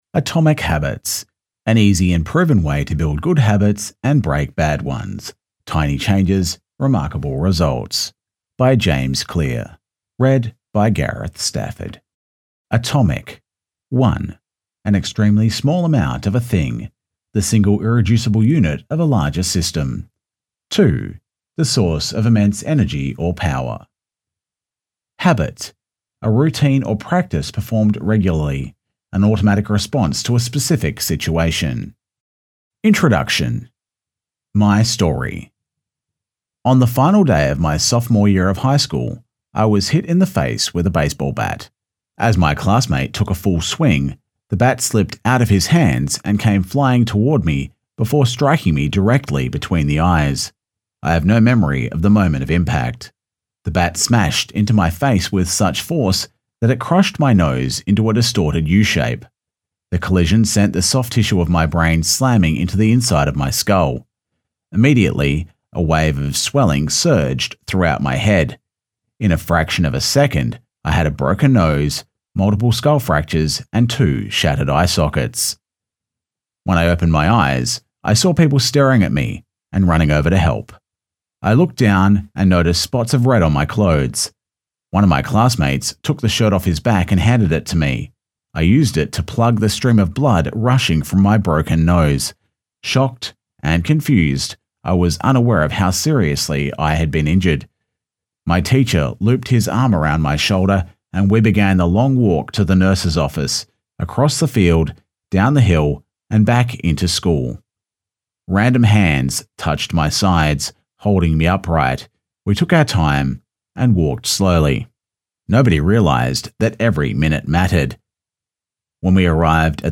With a modern, relatable sound (and a touch of sarcasm when the moment calls for it), he has sharp instincts and a structured approach that allow him to balance creativity and empathy with clarity and professio...
0804Atomic_Habits_Demo_-_Introduction.mp3